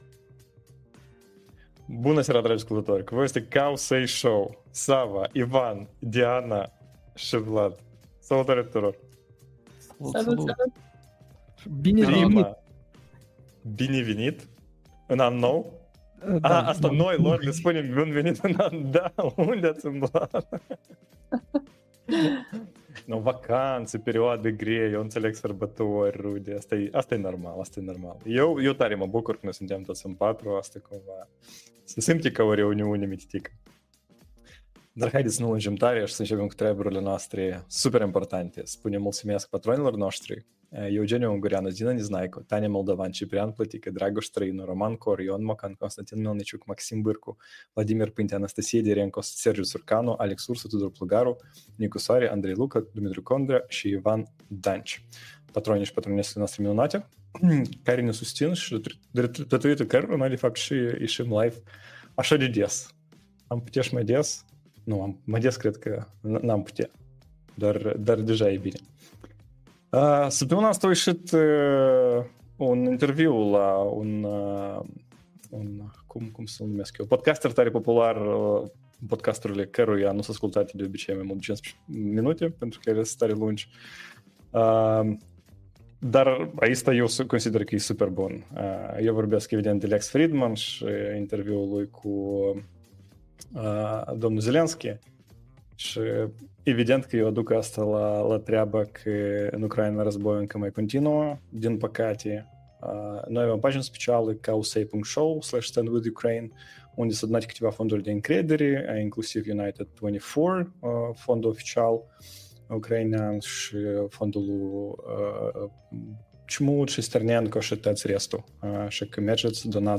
NVDIAaaaaaâââgh și probleme Adorabile January 09th, 2025 Live-ul săptămânal Cowsay Show.